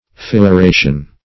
fissuration - definition of fissuration - synonyms, pronunciation, spelling from Free Dictionary
Search Result for " fissuration" : The Collaborative International Dictionary of English v.0.48: Fissuration \Fis`su*ra"tion\, n. (Anat.) The act of dividing or opening; the state of being fissured.